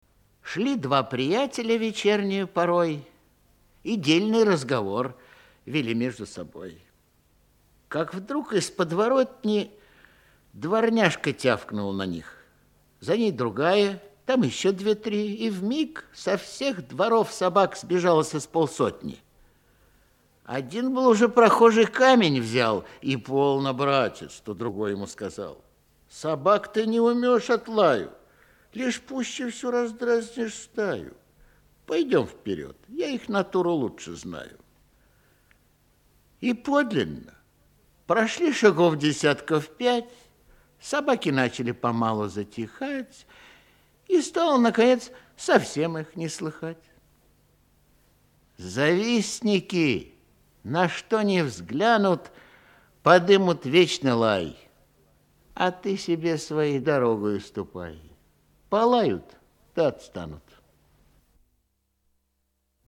1. «Исп. Игорь Ильинский – И.Крылов – Прохожие и собаки» /